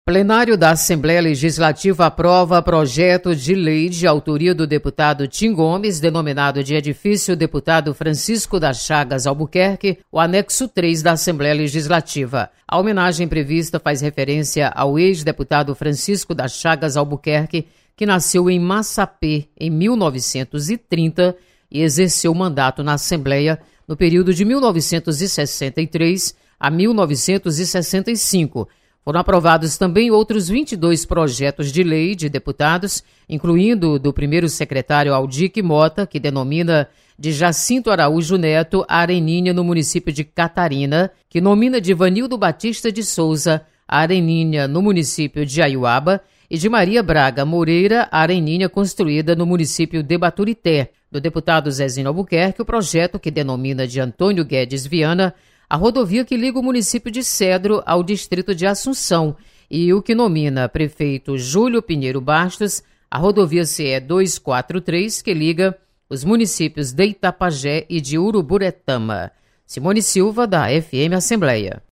Deputados aprovam projetos em votação nesta quarta-feira. Repórter